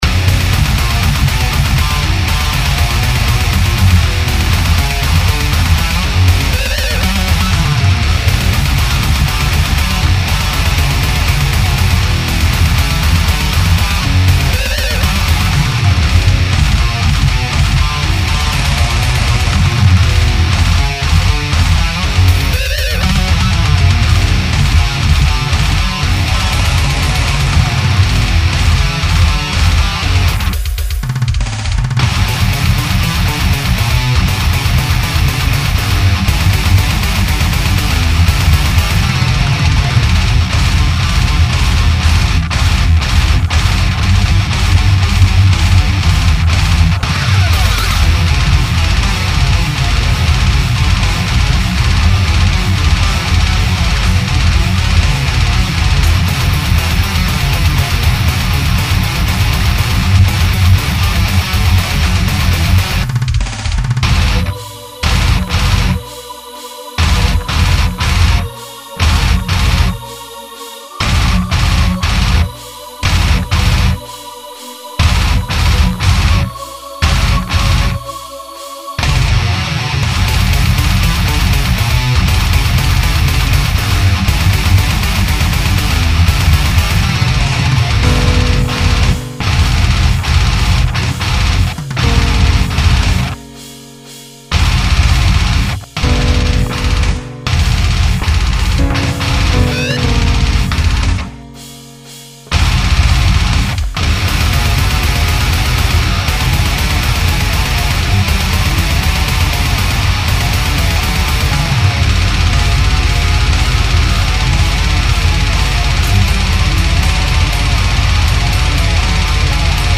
guitare: Ibanez Rg 370-B
batterie: fruity loops + best of de samples trouvés par ci par la sur le web
A vrai dire je garde les memes réglages depuis un bon moment déja, ayant eu une année chargée je n'ai pas pu énormément les changer et je dois dire qu'autant ce son froid et robotique me plaisait bien il y'a 1 ou 2 ans autant aujourd'hui je trouve qu'il sonne de plus en plus vide et creux.
Autant le kick passe plutôt bien pour le style. Par contre la caisse claire sonne super maigre à mon goût (elle fait juste "tick!").
- les cymbales, à part la charley sont pas mal sous mixées. En fait l'équilibre batterie est assez étrange je trouve.
Mais dans l'ensemble, à part des progs pas très humaines par moment (batterie et clavier), ça sonne plutôt bien !